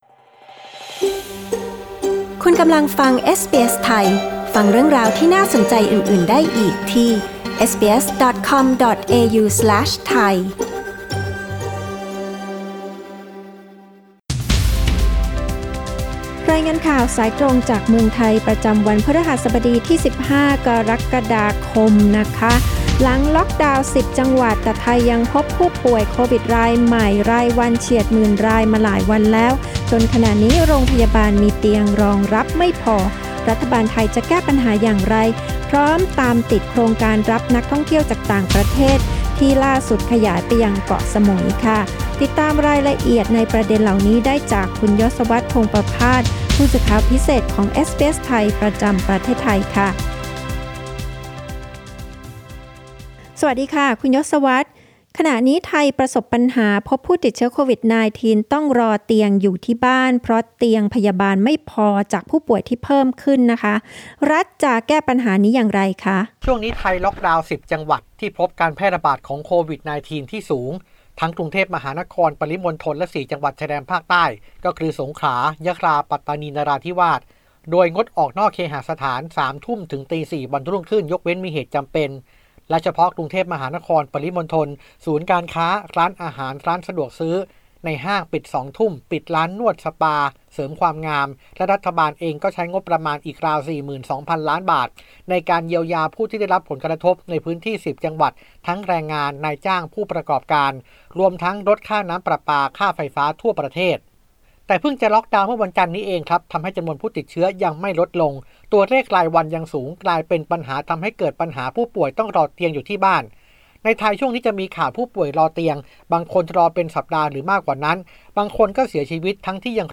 รายงานสายตรงจากเมืองไทย โดยเอสบีเอส ไทย Source: Pixabay